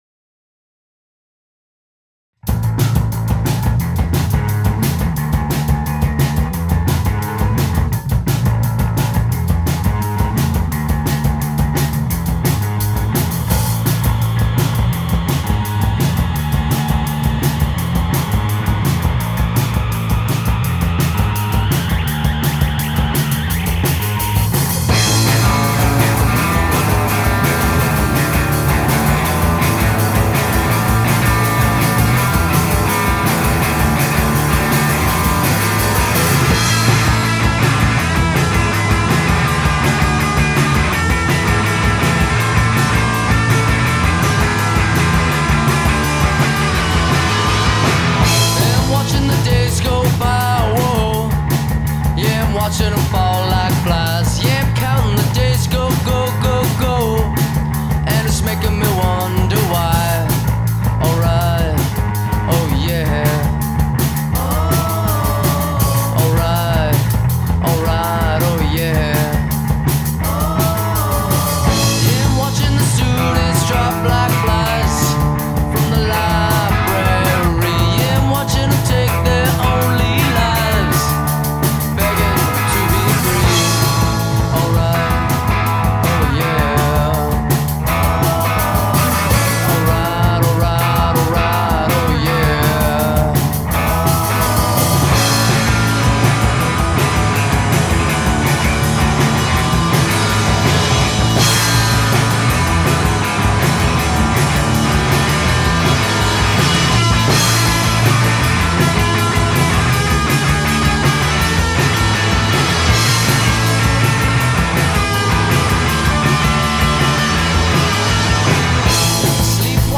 Sul fondo alle loro canzoni resta sempre qualcosa di freddo.